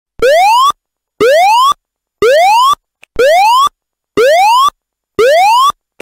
Whoop x 6
Whoop
Whoop.mp3